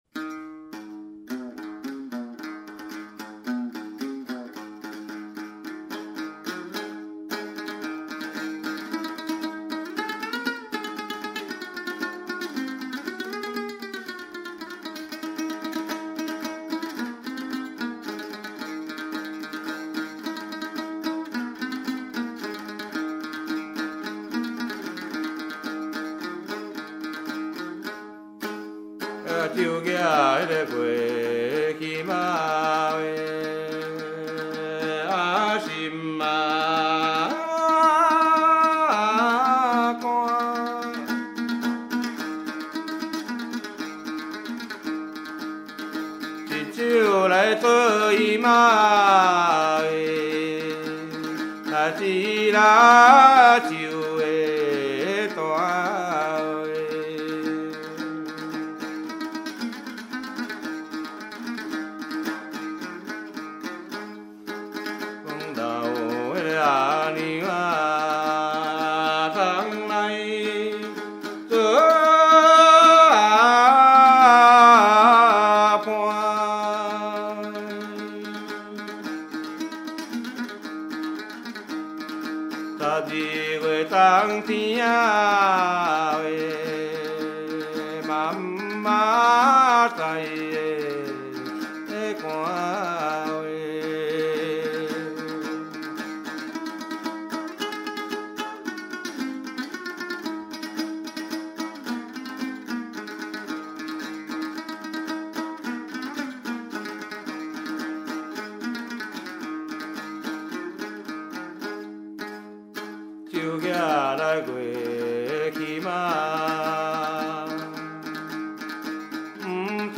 ◎制作群 ： 演出：月琴,壳仔弦｜
18首原汁原味的素人歌声，在粗哑中满怀真性情，那是上一代的回忆，这一代的情感，下一代的宝藏！
对唱